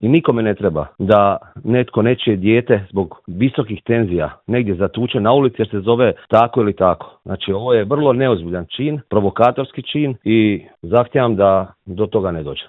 Vukovarski gradonačelnik Marijan Pavliček u Intervjuu Media servisa kaže da je grad već danima pun hodočasnika: